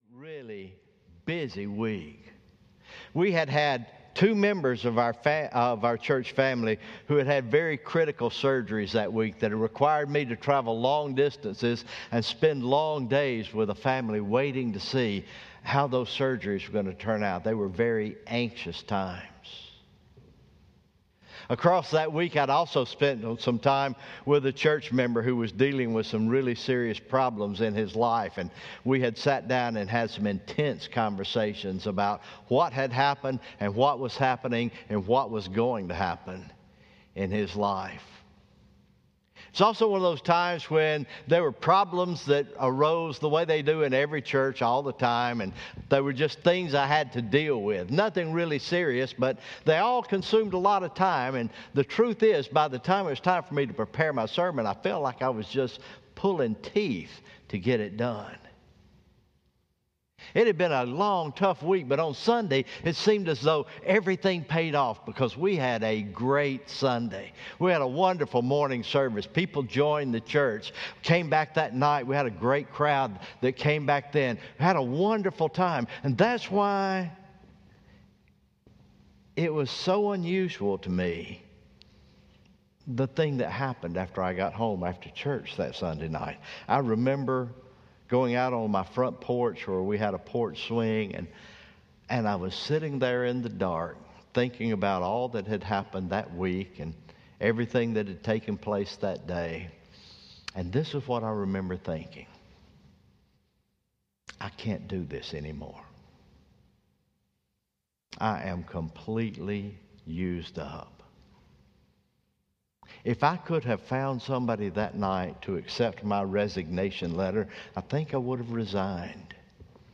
Morning Worship Memorial Day Weekend